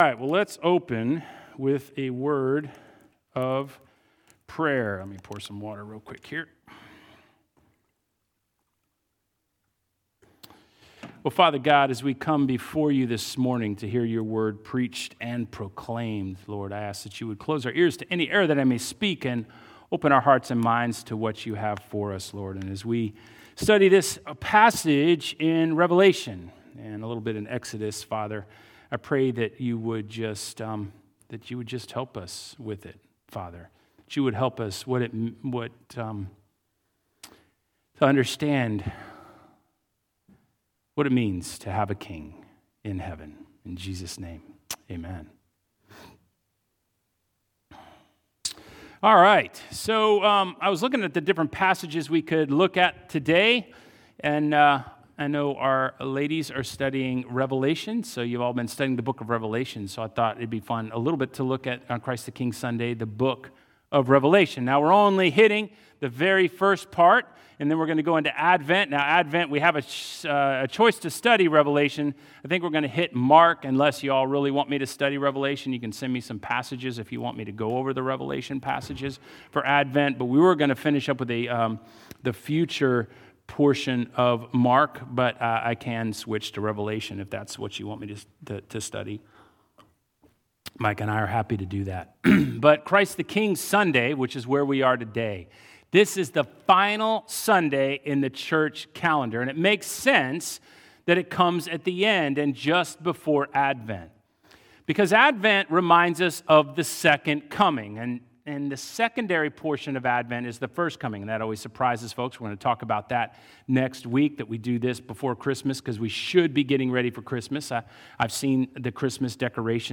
Sermons by St. Andrews Anglican Church